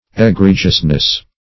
Egregiousness \E*gre"gious*ness\